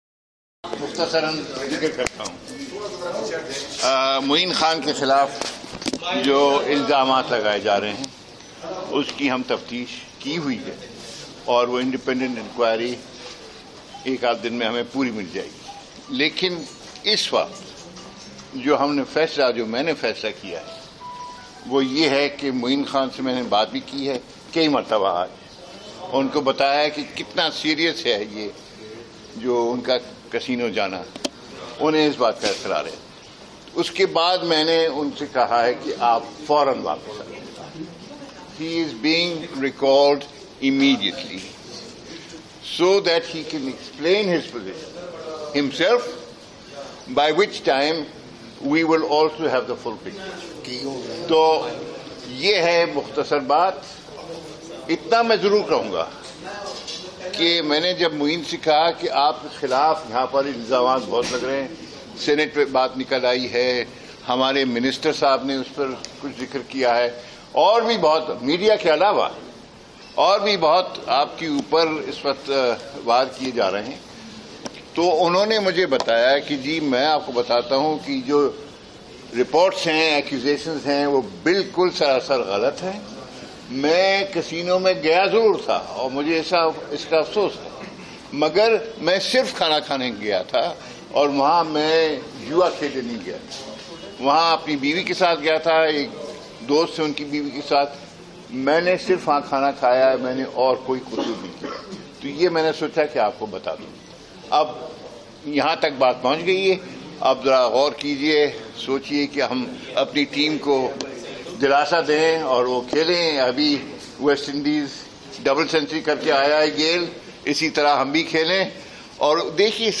Media Talk: Chairman PCB at Gaddafi Stadium, Lahore